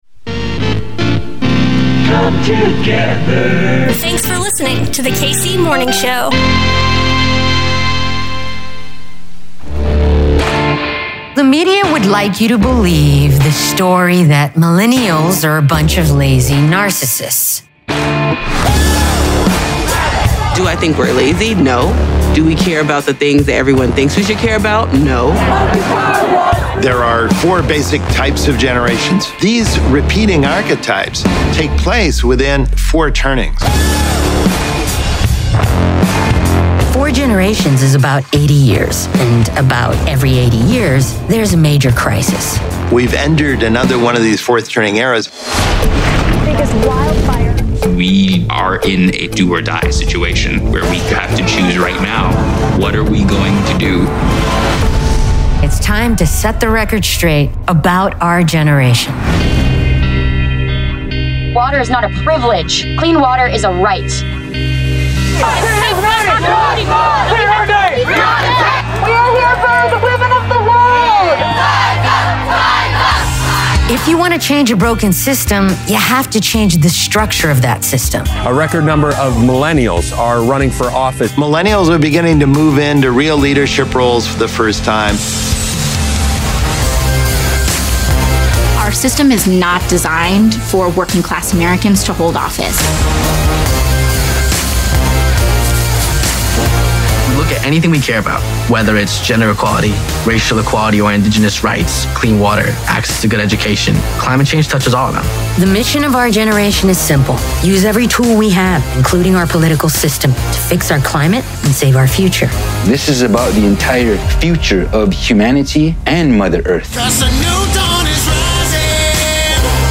A lighthearted take on Kansas City news, events, sports and more.